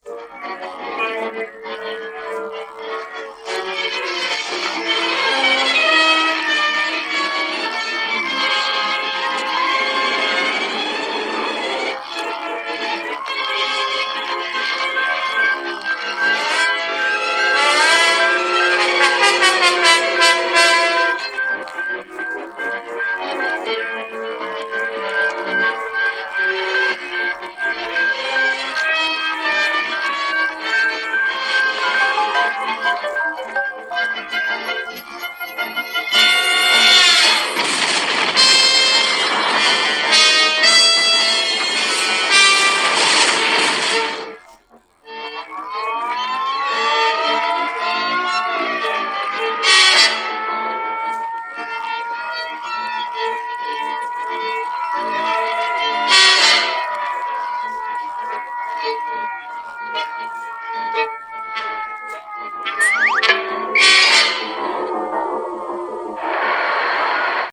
Уважаемые коллекционеры инструментальной музыки.
По звучанию авангардная музыка напоминает и Муравлева, и Парцхладзе, и Гевиксиана.